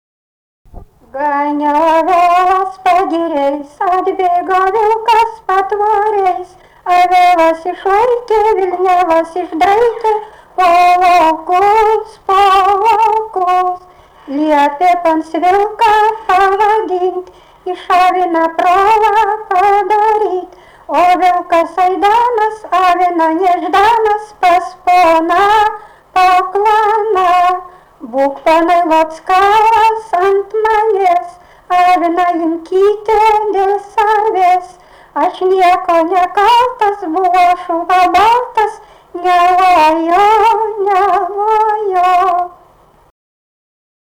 smulkieji žanrai
Erdvinė aprėptis Suvainiai
Atlikimo pubūdis vokalinis